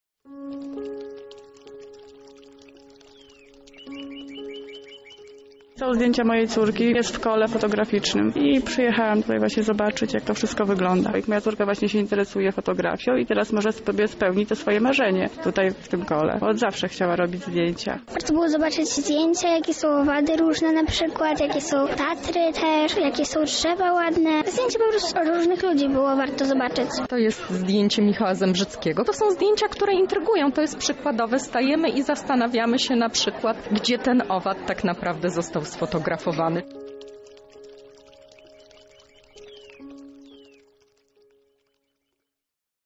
Z uczestnikami wydarzenia rozmawiała nasza reporterka